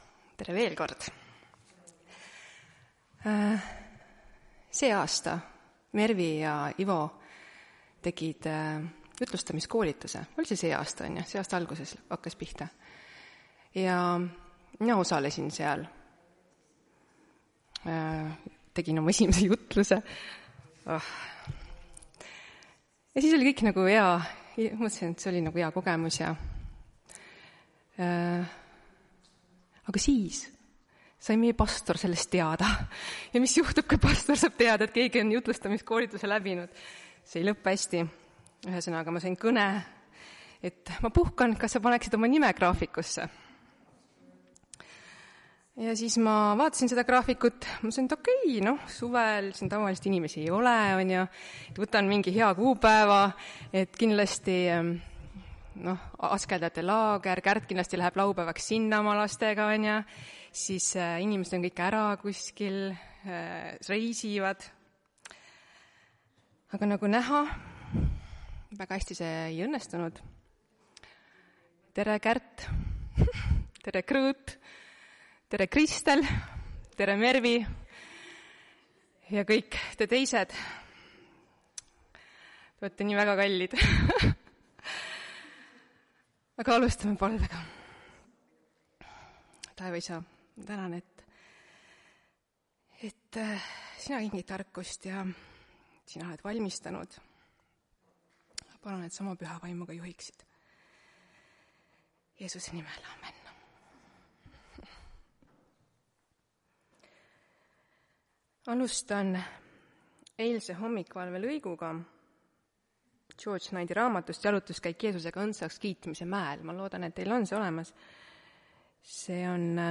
Tartu adventkoguduse 20.07.2024 hommikuse teenistuse jutluse helisalvestis.